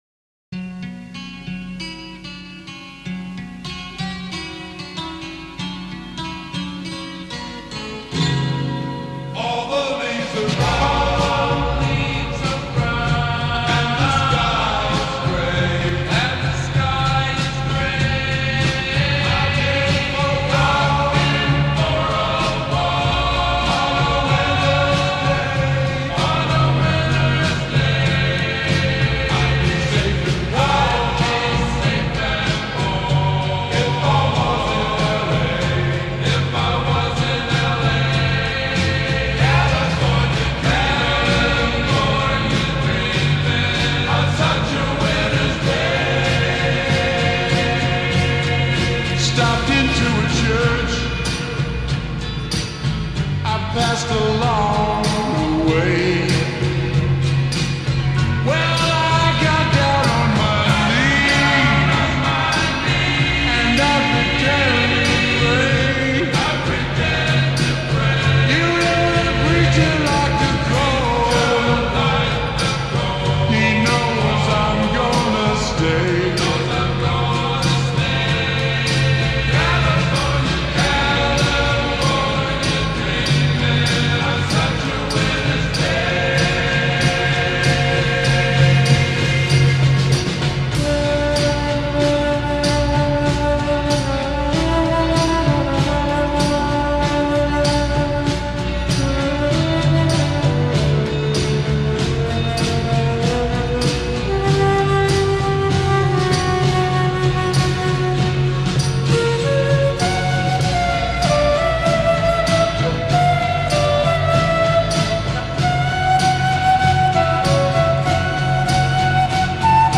با ریتمی آهسته شده